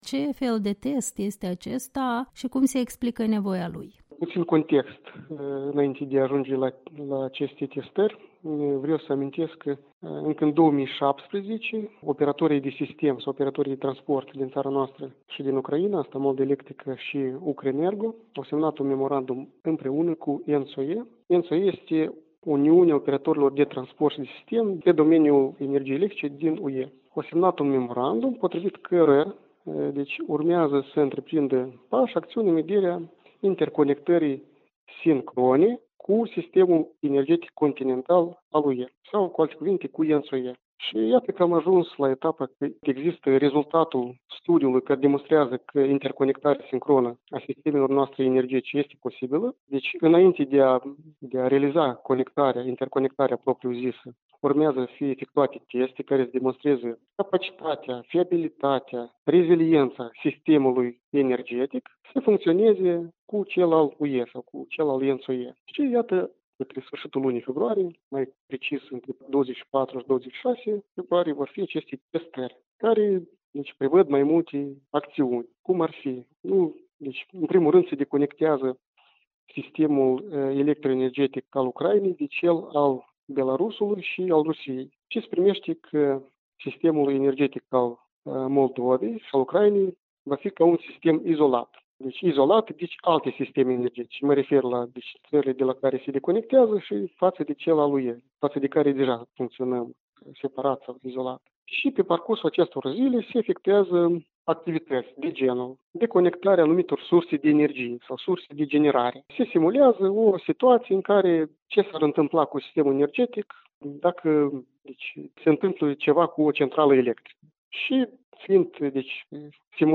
Interviu cu secretarul de stat de la Ministerul Infrastructurii și Dezvoltării Regionale, Constantin Borosan